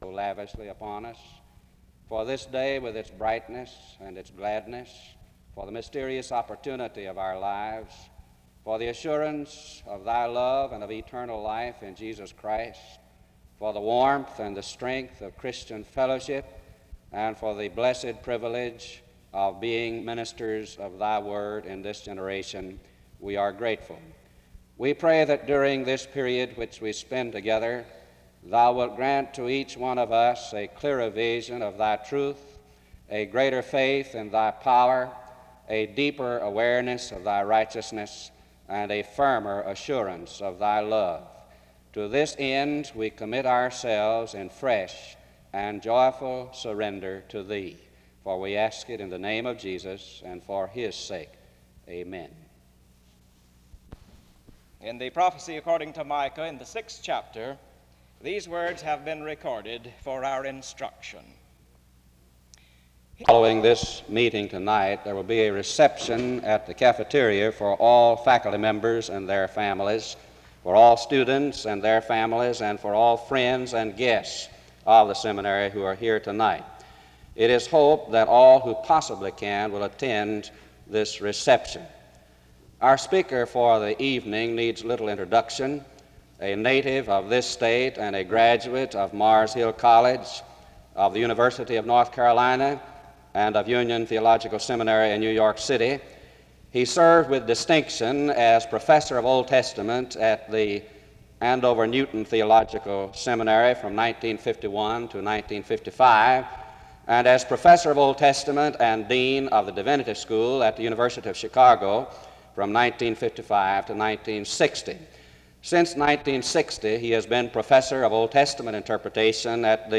The service opens with a prayer from 0:00-0:52. An announcement and introduction to the speaker is given from 1:02-2:42.
Location Wake Forest (N.C.)